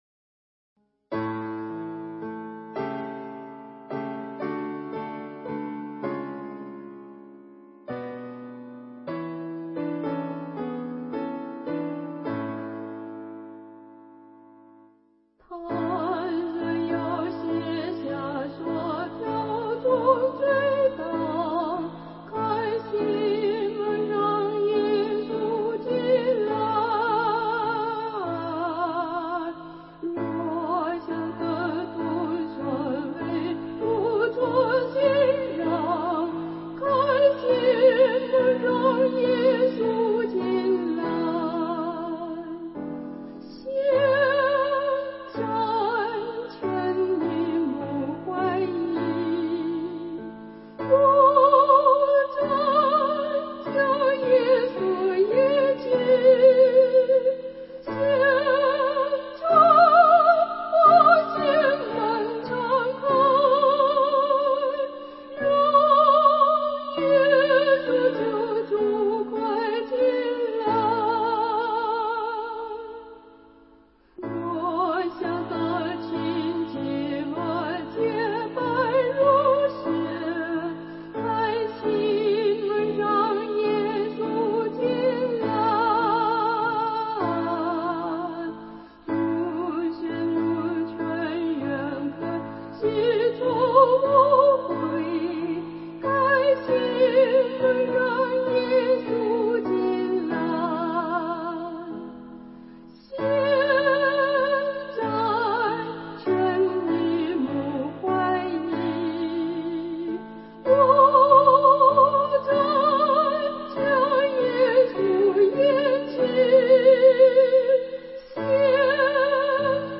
导读：本颂赞诗歌歌谱采用2017年修订版，录音示范暂用旧版，将逐渐更新。
伴奏